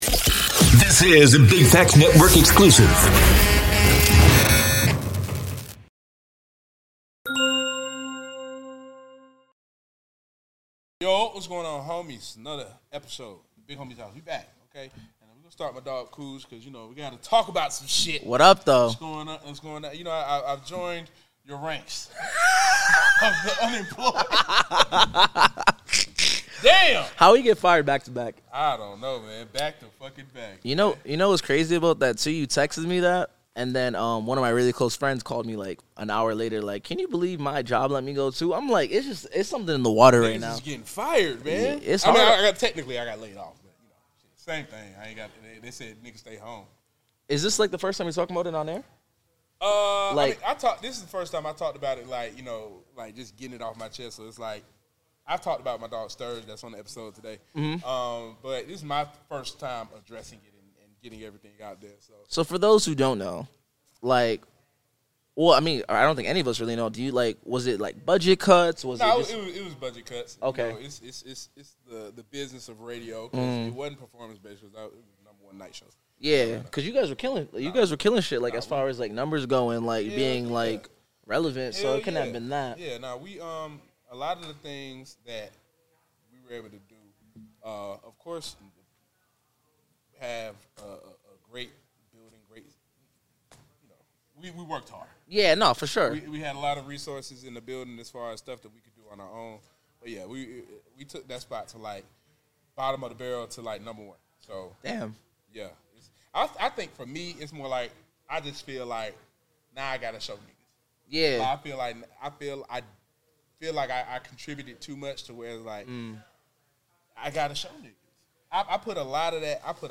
Tap in for real talk, hot takes, and unfiltered conversations! 🎧🔥